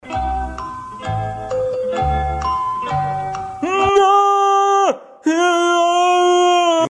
hyppie-pleure.mp3